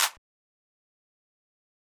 SizzClap1.wav